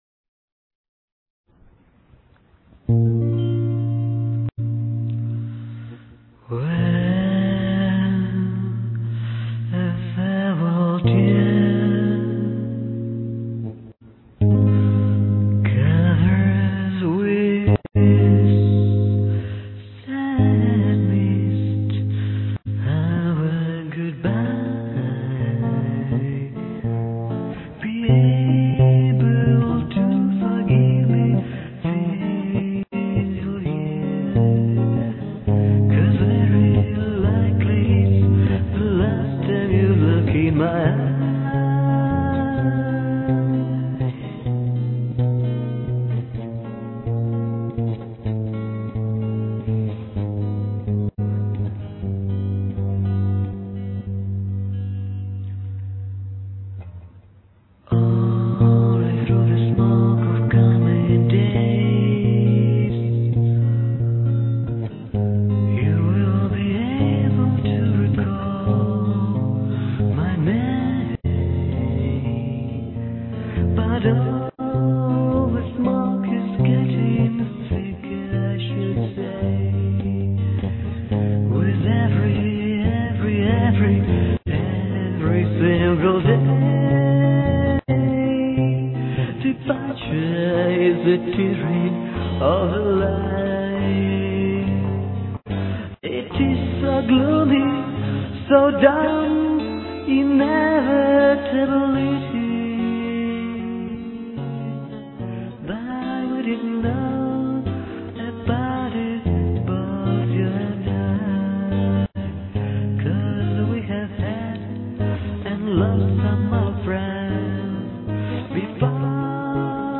All verses are in soft bosa-nova style (a-la A.-C. Jobim) with acoustic
guitar, except Refr which should be played with lead drive guitar.